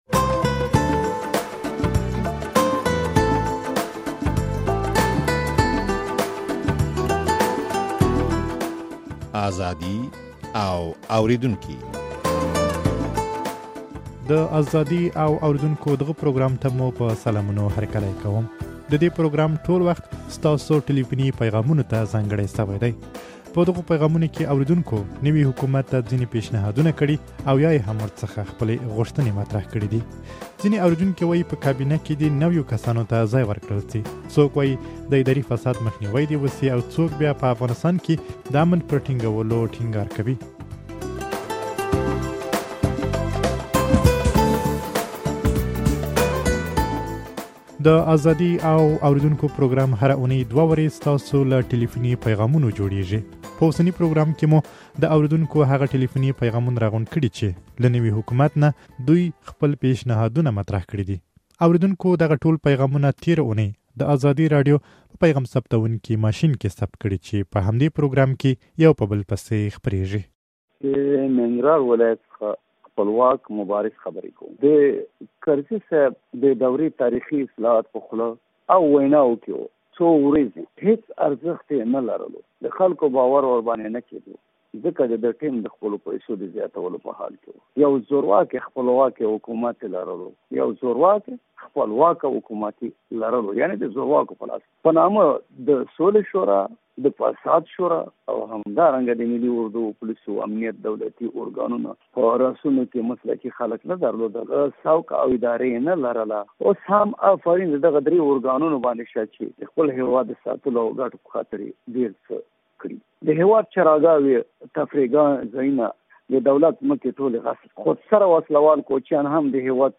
د دې پروګرام ټول وخت ستاسو ټليفوني پيغامونو ته ځانګړى شوى دى. په دغو پيغامونو کې اورېدونکو نوي حکومت ته ځينې پېشنهادونه کړي